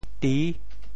“娣”字用潮州话怎么说？
娣 部首拼音 部首 女 总笔划 10 部外笔划 7 普通话 dì 潮州发音 潮州 di6 文 中文解释 娣 <名> (会意。